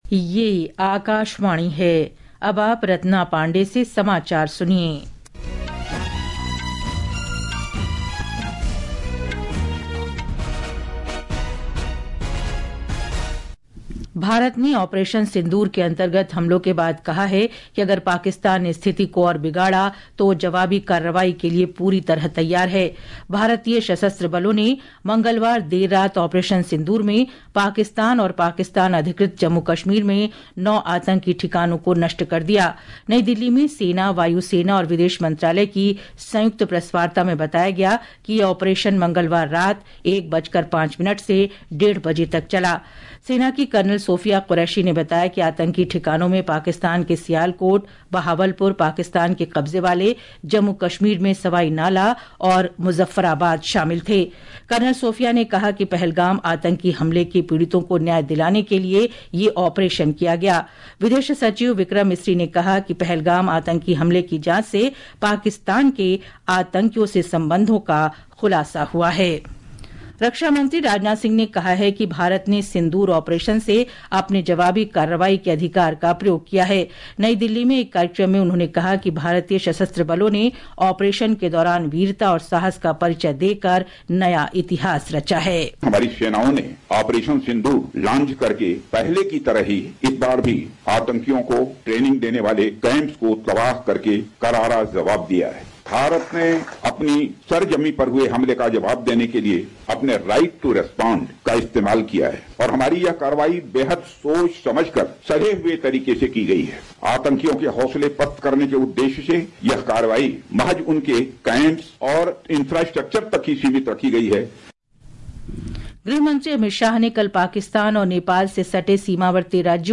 प्रति घंटा समाचार | Hindi